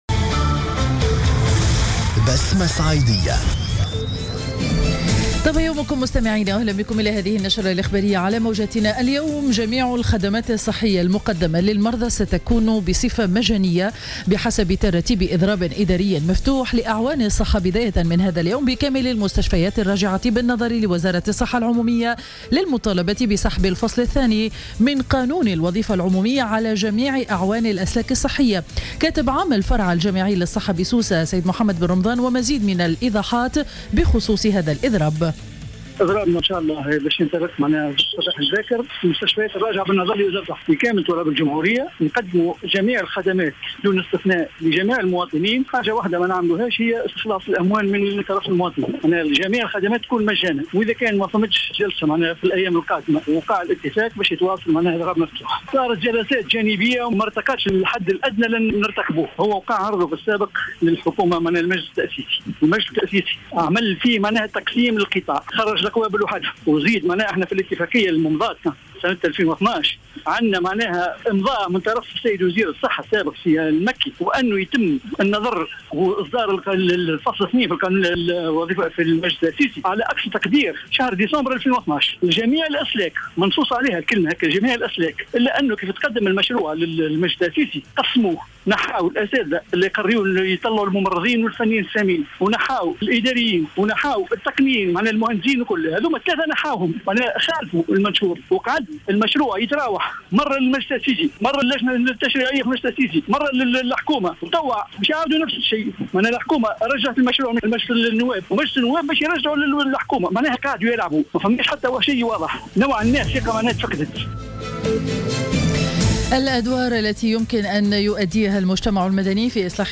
نشرة أخبار السابعة صباحا ليوم الإثنين 25 ماي 2015